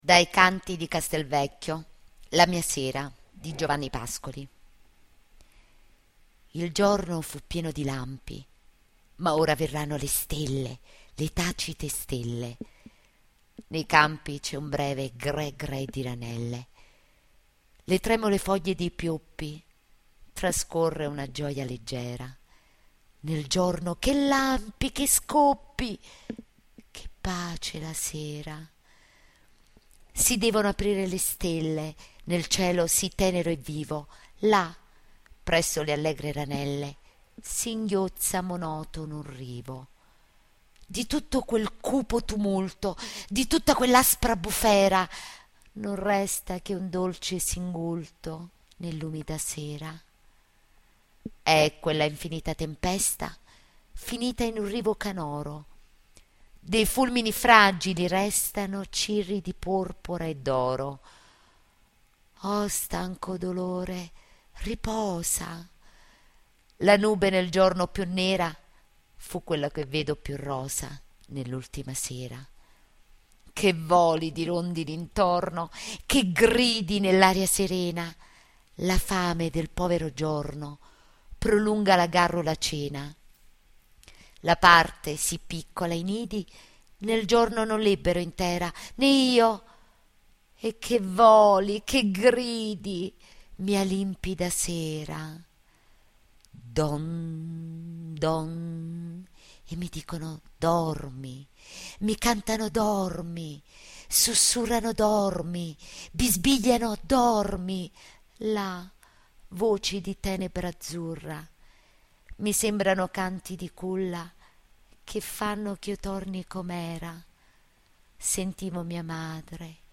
Poesie recitate da docenti